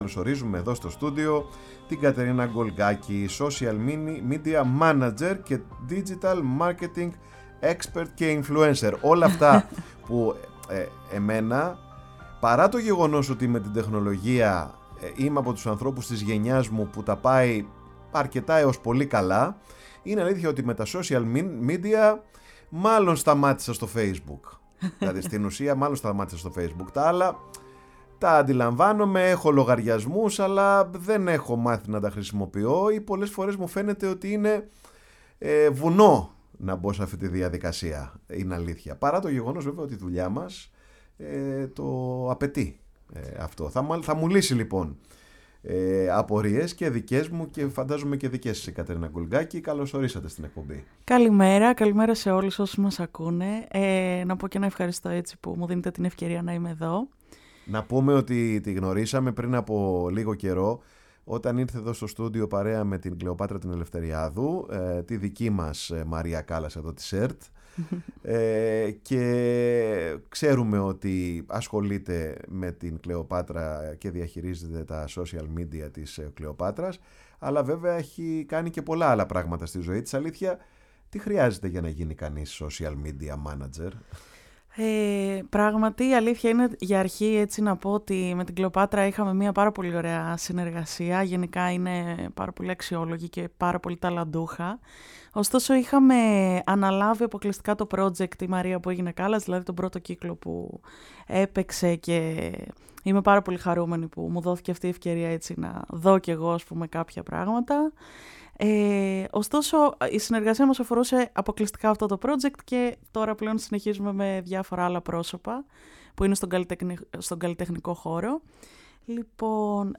φιλοξένησε σήμερα στο στούντιο